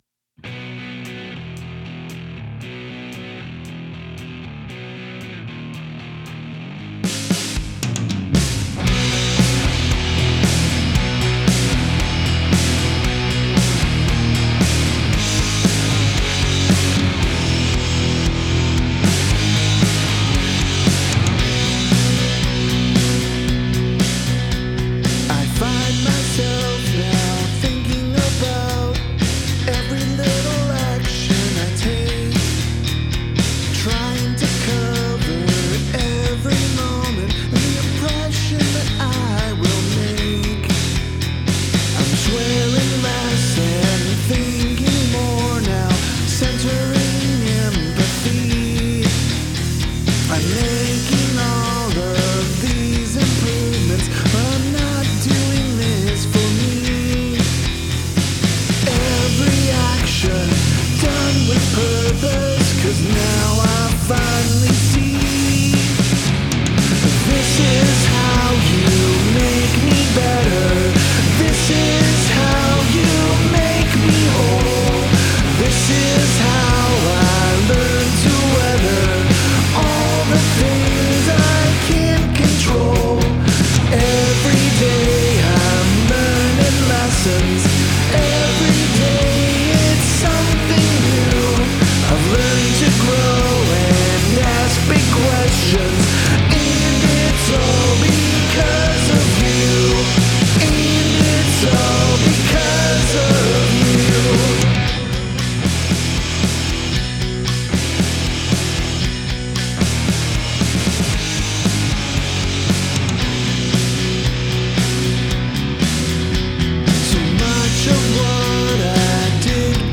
Musically, this song is pretty much all diatonic. It makes heavy use of minor III, but otherwise is fairly straightforward.
It was recorded in my home studio, using Amplitube for the heavy guitars and Line 6 Helix simulation for the clean ones. Drums are Blasting Room and vocals are via my SM-7B.